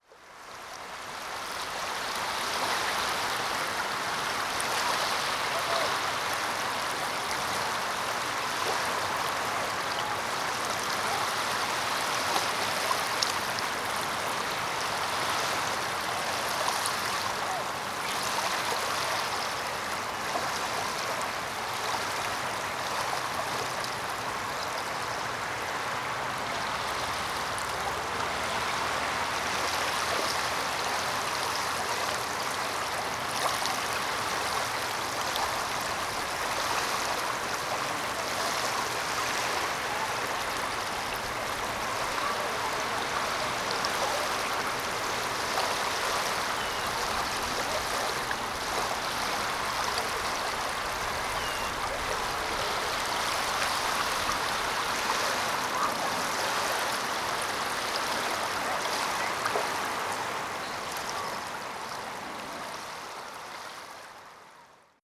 the sound of gentle waves at departure bay on a perfect spring day [audio.
On the return, decided to try a sound recording of the gentle waves. You can hear some birds and the sounds of people down the beach, but mostly it’s the gentle waves.
departure-bay-waves.wav